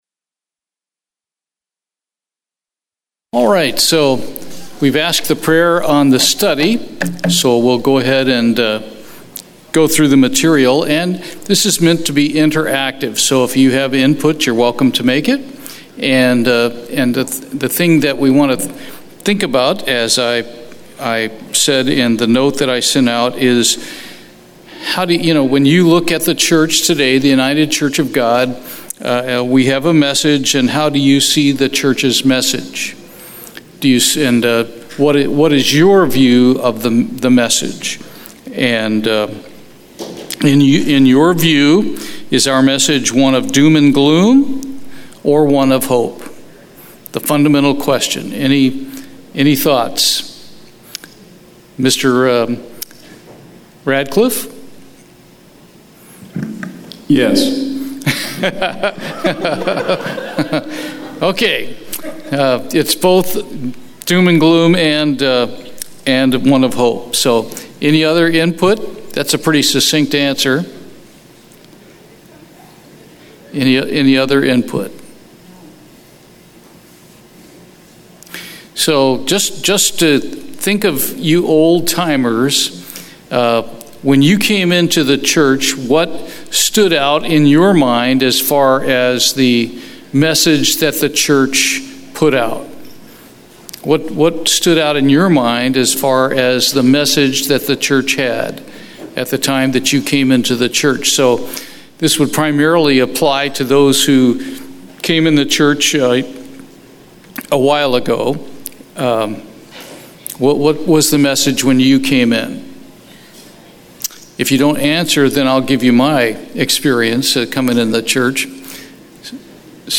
Bible Study, Retention of the Youth - Why Don't They Stay?